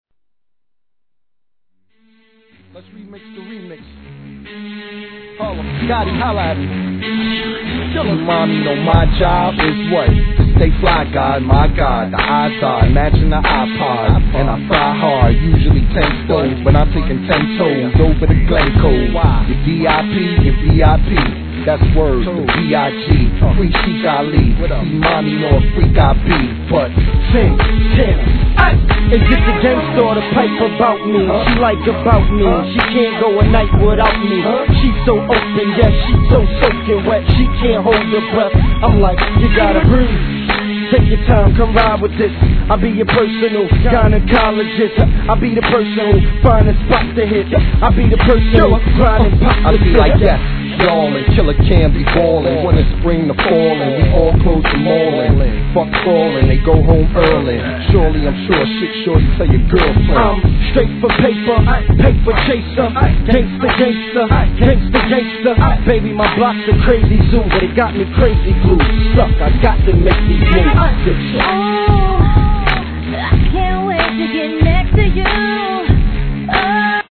HIP HOP/R&B
HEAVYなビートで歌い上げた人気曲